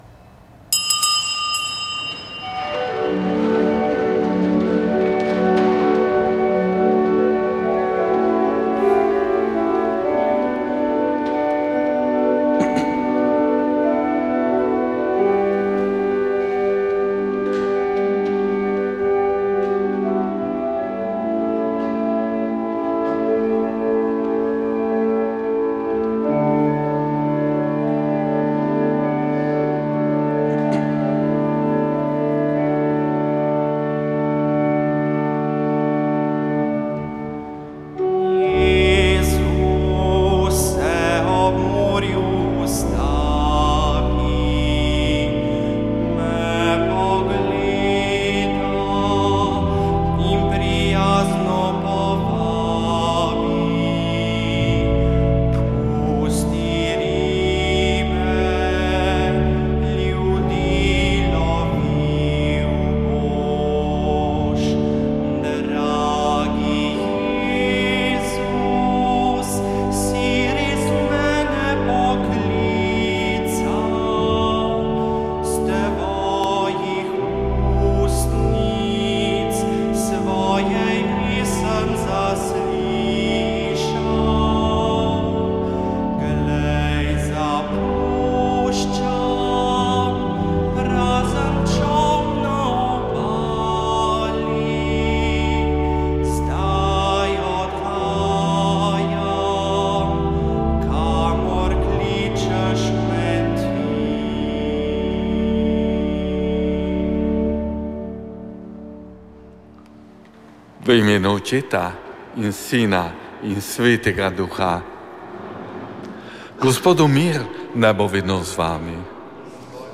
Slovenska akademija znanosti in umetnosti je ob stoti obletnici rojstva akademika Alojza Rebule pripravila znanstveni posvet z naslovom Alojz Rebula: Slovenec med zgodovino in nadčasnim.